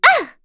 Dosarta-Vox_Attack2.wav